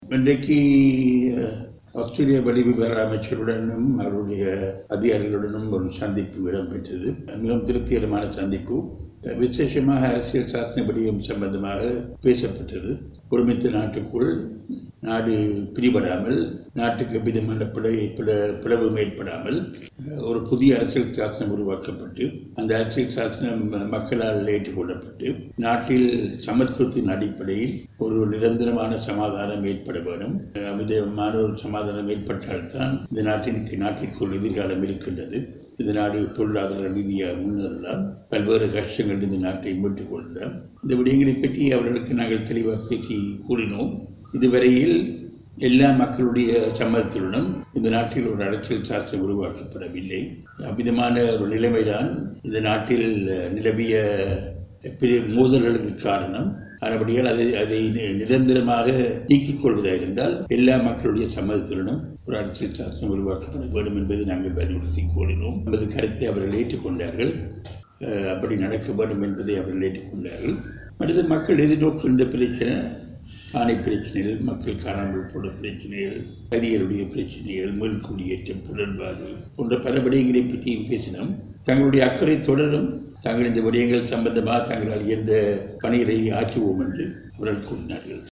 இந்த சந்திப்பு தொடர்பில் எதிர்கட்சி தலைவர் கருத்து வெளியிடுகிறார்.